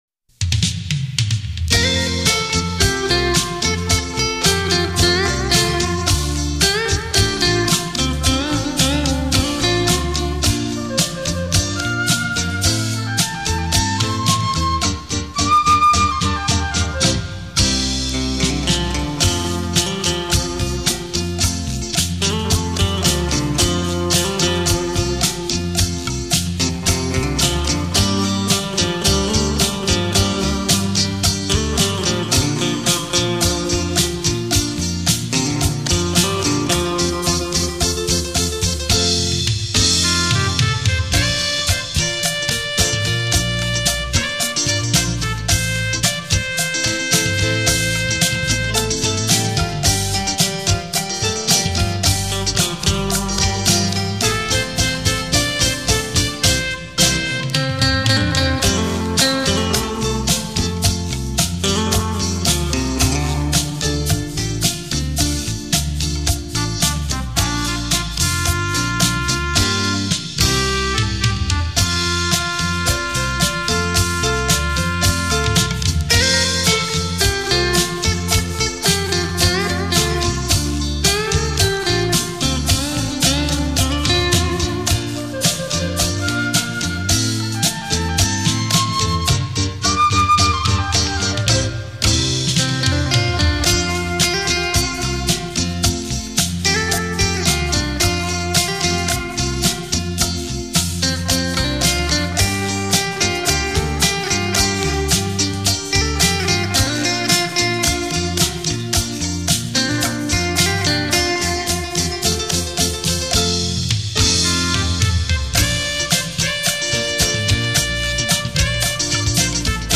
錄音：樂韻錄音室